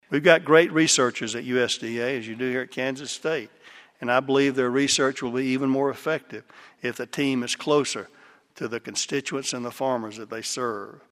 Kansas State welcomed U.S. Agriculture Secretary Sonny Perdue to campus Thursday as the 179th speaker for the Landon Lecture series.